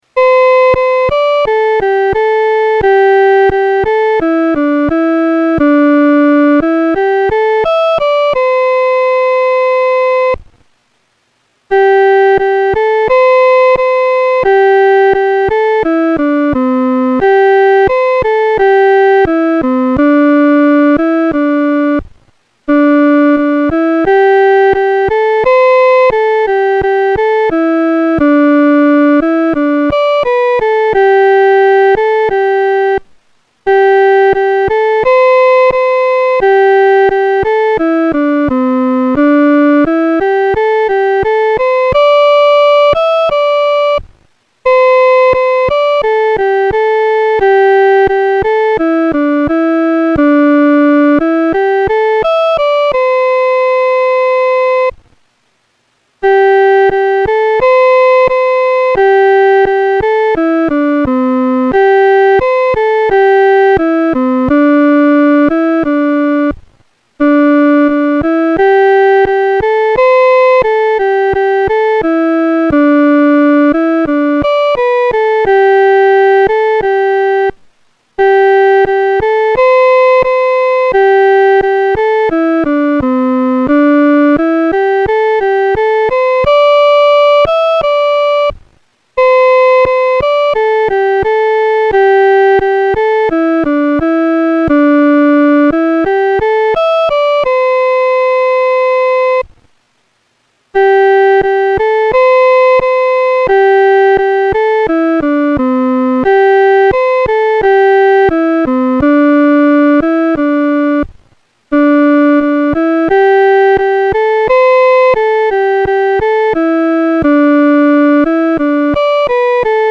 伴奏
女高